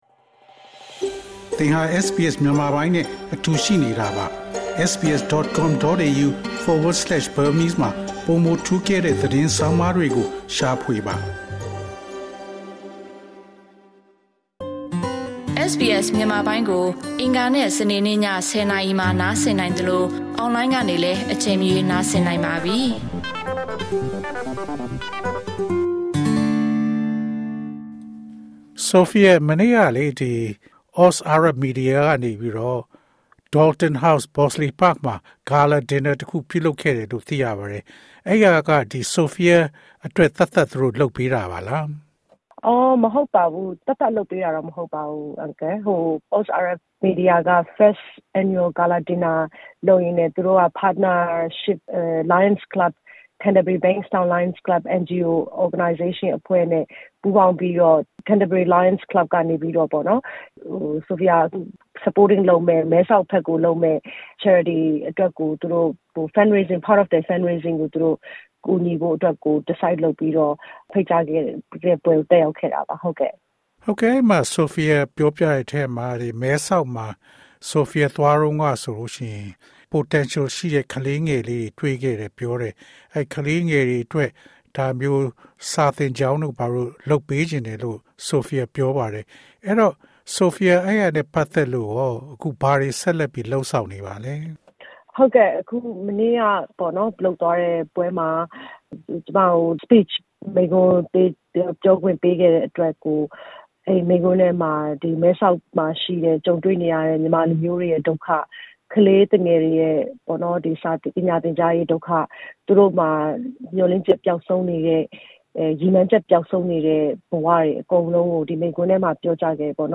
အင်တာဗျူး